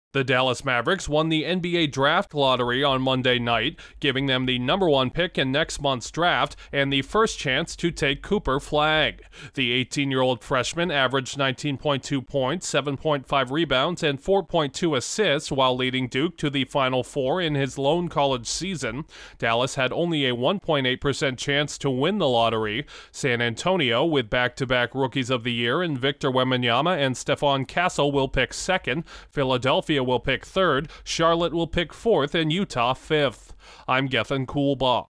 Last year’s NBA runner-up is the surprise winner of this year’s top draft selection. Correspondent